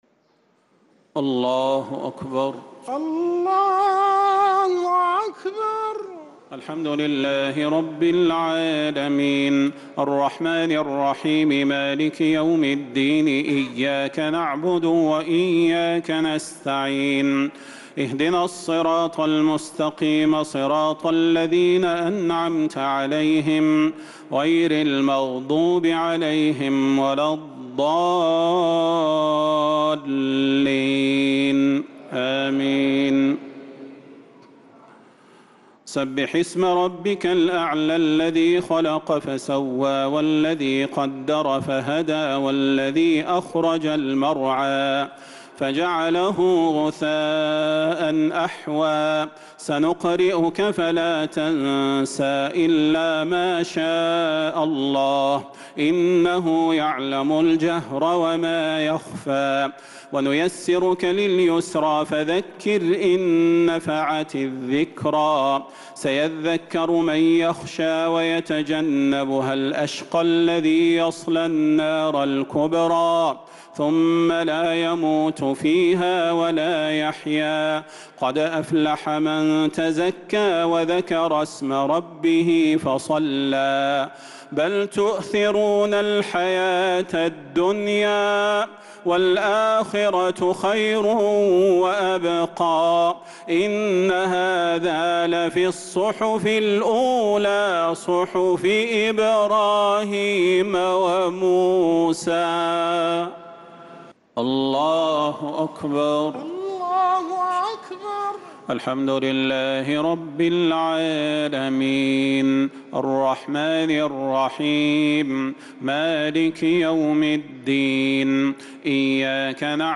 الشفع و الوتر ليلة 21 رمضان 1446هـ | Witr 21 st night Ramadan 1446H > تراويح الحرم النبوي عام 1446 🕌 > التراويح - تلاوات الحرمين